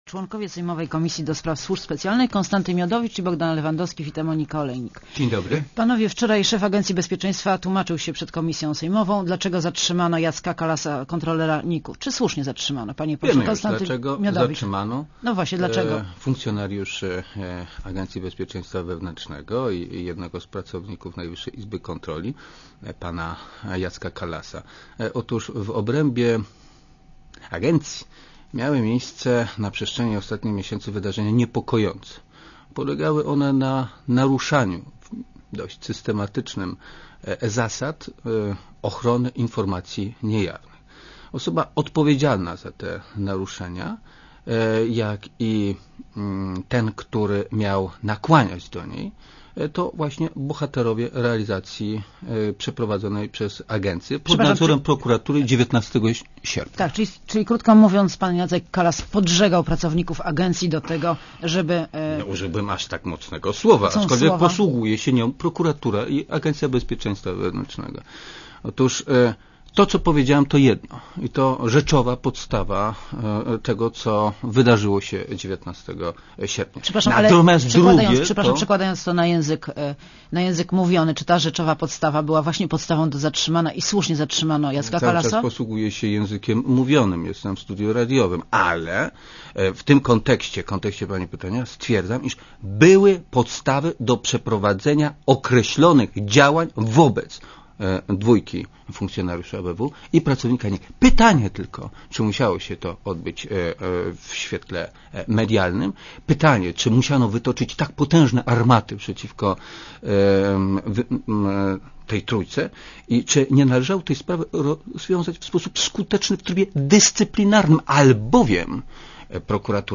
Bogdan Lewandowski (z lewej) i Konstanty Miodowicz w Radiu Zet (RadioZet)